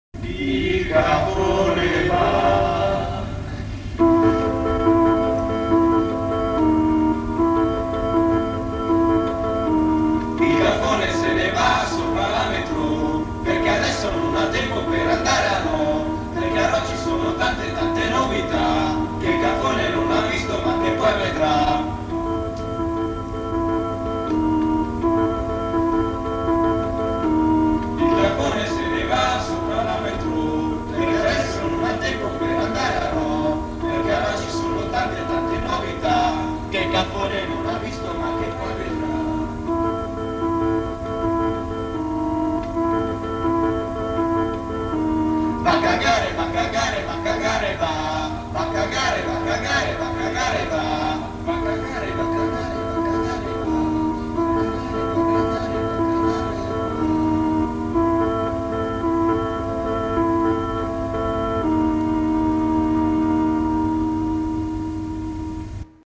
pianolina Bontempi
coro di ubriachi